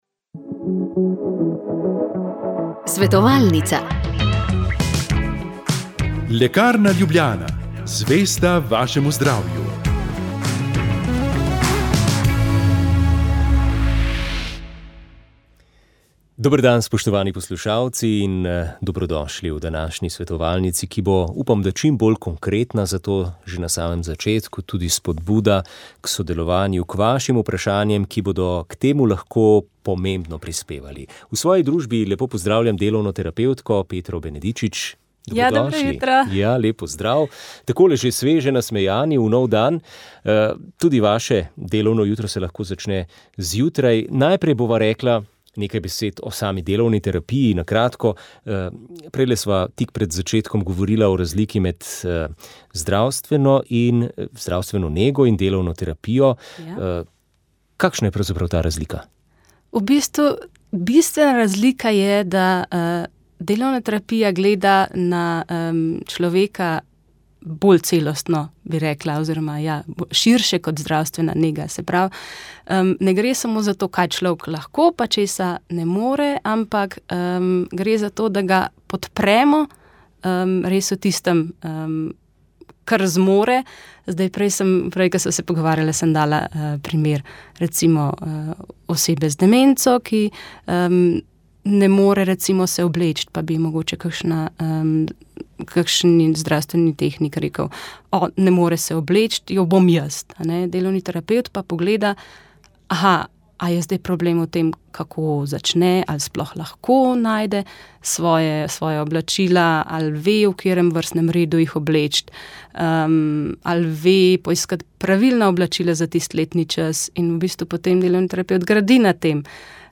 Molile so redovnice - Marijine sestre.